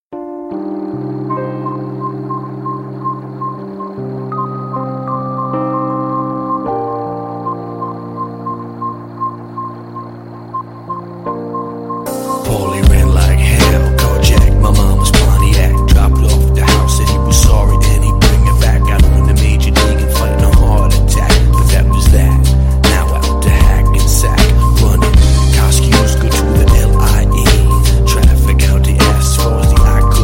Dekódování mp3 - lupající zvuk